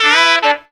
HARM RIFF 5.wav